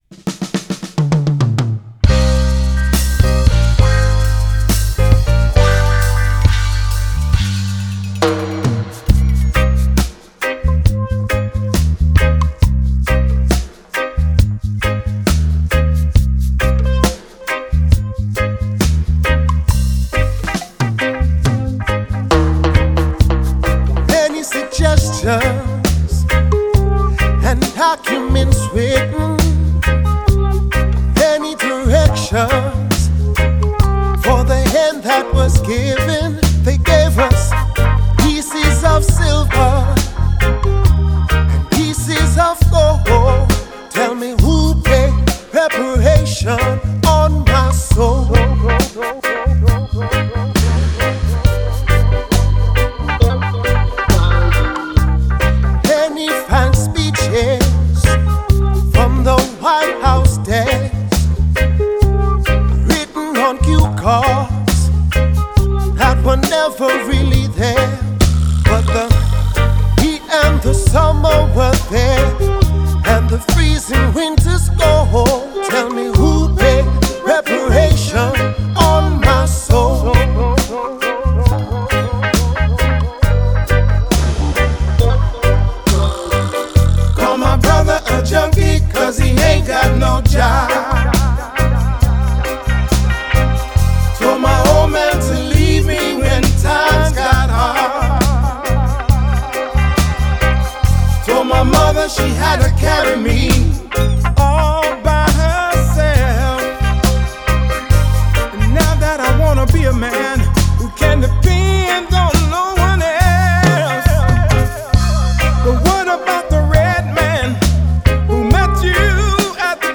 The album features live reggae dub mixes